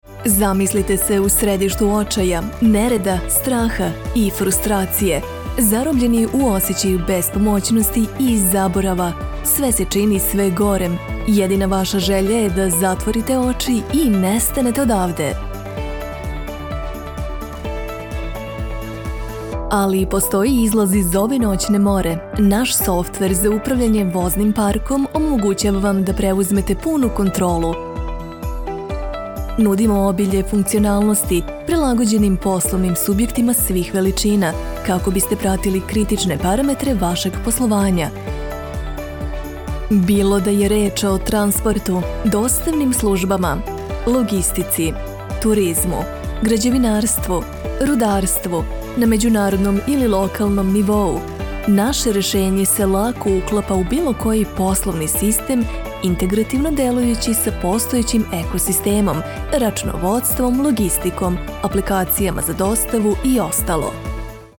Radio Imaging
Looking for the right neutral female Serbian voice-over artist – accent-free – for your project?
Vocal booth